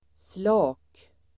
Uttal: [sla:k]